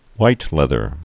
(wĭtlĕthər, hwĭt-)